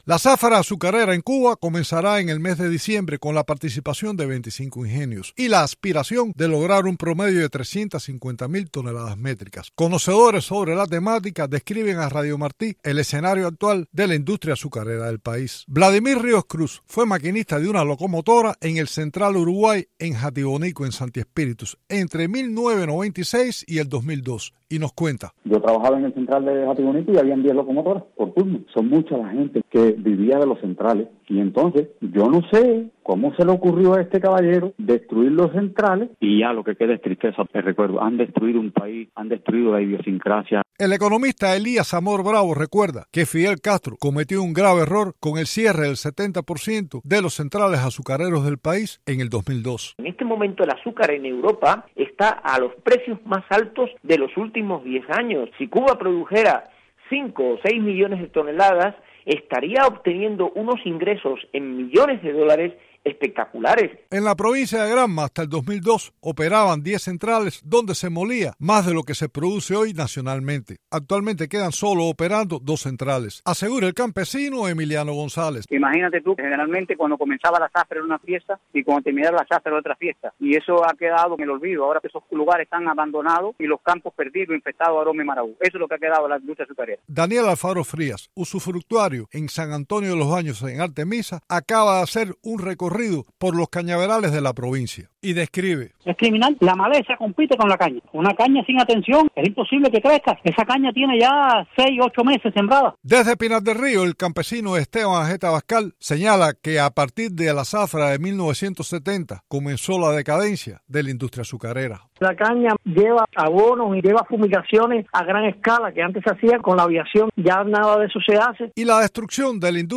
Testimonios de expertos sobre el estado de la industria azucarera en Cuba